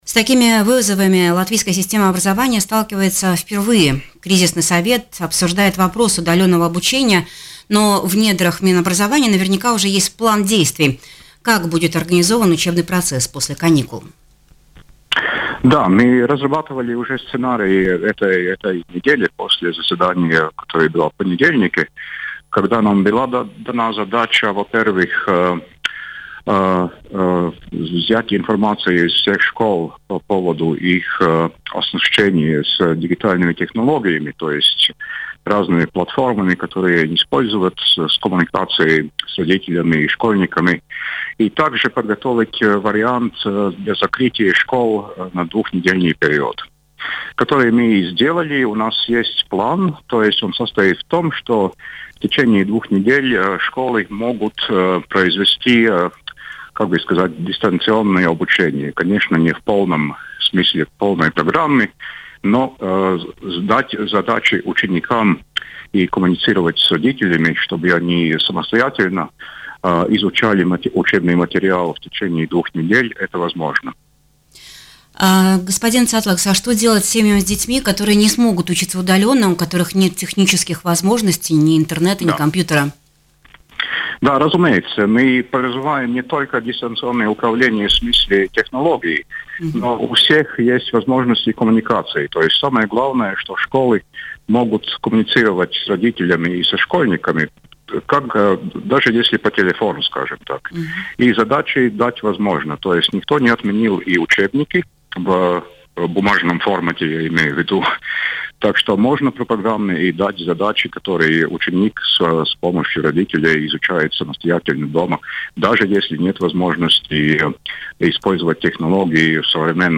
В независимости от развития ситуации с коронавирусом COVID-19 в Латвии, все школьники будут обеспечены учебными материалами, и обучение продолжится. Об этом в эфире радио Baltkom заявил глава Государственного центра содержания образования Гунтар Цатлакс.